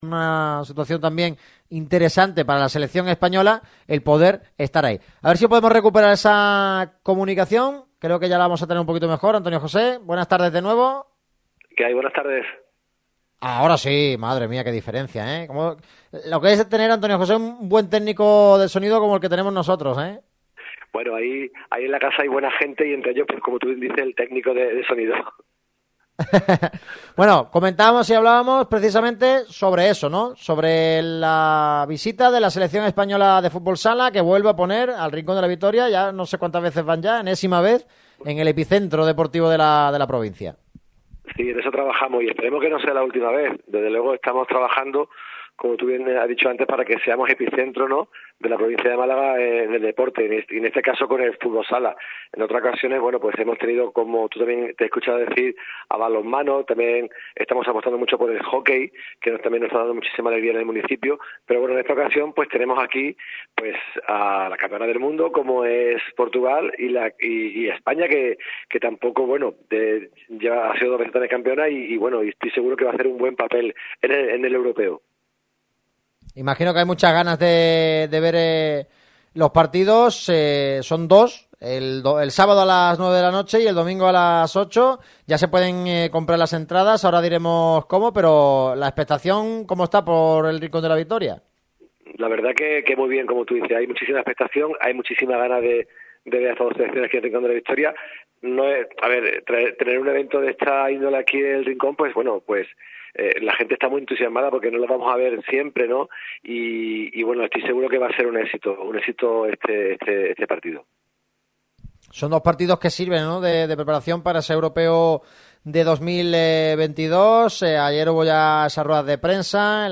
Antonio José Martín, concejal de deportes del Rincón de la Victoria
Hablamos con Antonio José Martín, concejal de Deportes del Rincón de la Victoria; en la víspera de esa cita en materia de fútbol sala: España y Portugal jugarán este fin de semana dos amistosos en la localidad malagueña.